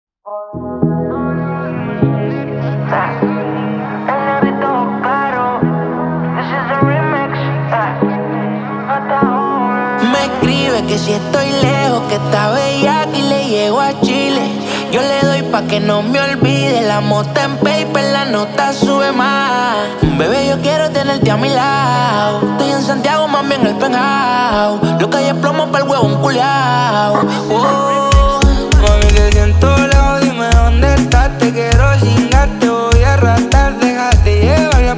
Urbano latino Latin
Жанр: Латино